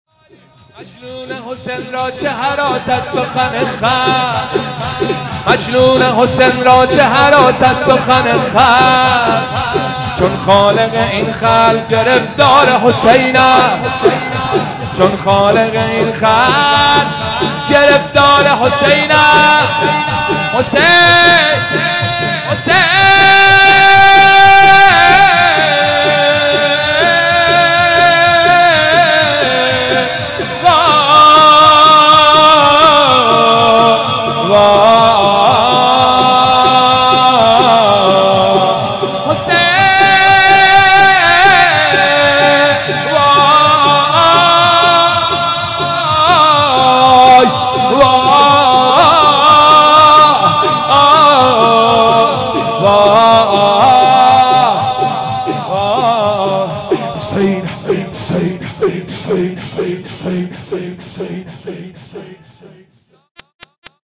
مداحی شب اول محرم 1399 با نوای حاج حسین سیب سرخی